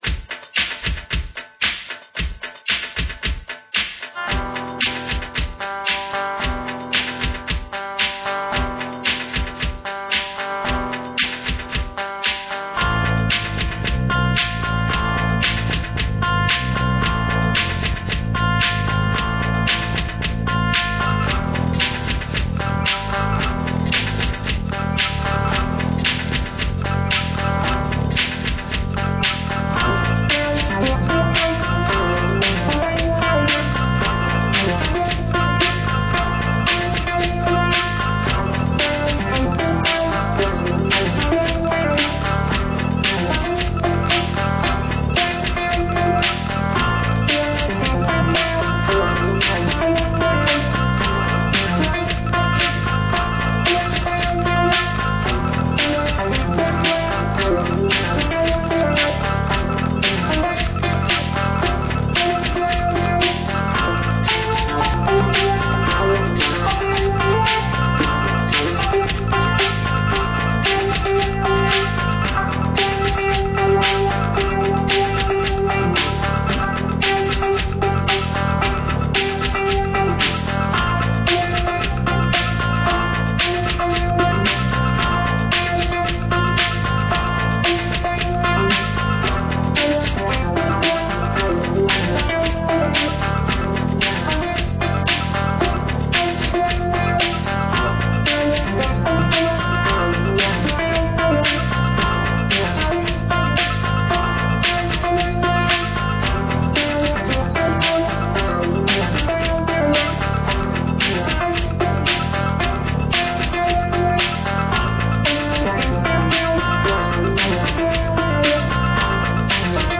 Upbeat pop guitar and keyboards.
Royalty Free Music for use in any type of
Tags: pop